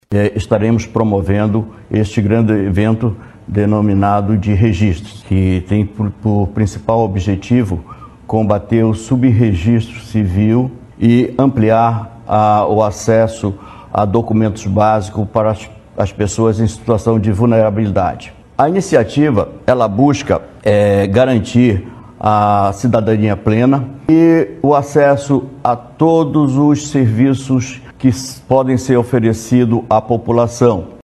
O juiz auxiliar da Corregedoria-Geral, Roberto Taketomi, explica que, nesta edição de 2025, o evento no Centro de Convenções Vasco Vasques oferece à população acesso a diversos serviços básicos.